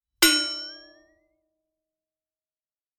Звуки ударов предметов